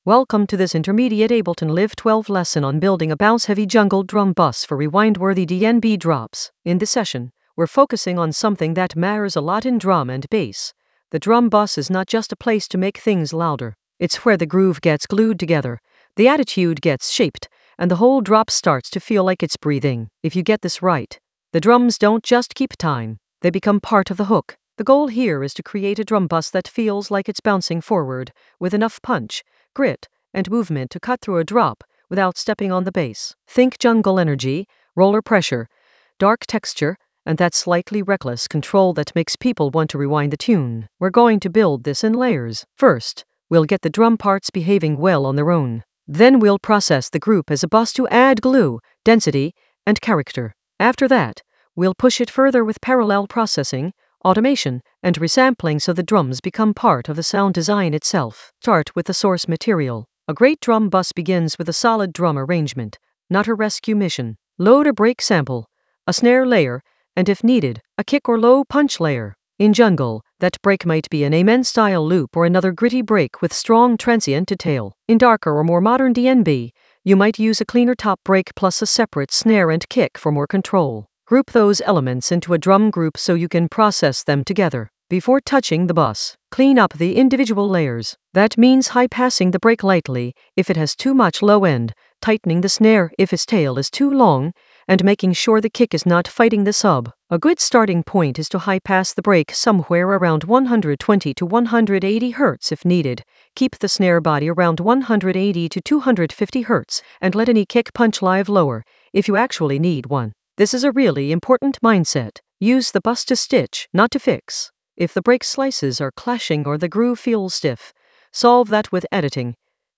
An AI-generated intermediate Ableton lesson focused on Bounce jungle drum bus for rewind-worthy drops in Ableton Live 12 in the Sound Design area of drum and bass production.
Narrated lesson audio
The voice track includes the tutorial plus extra teacher commentary.